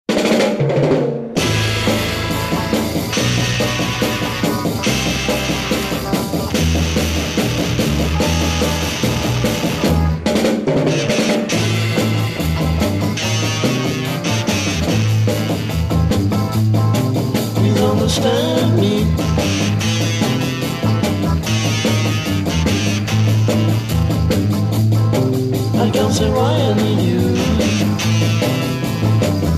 Garage beat psychédélique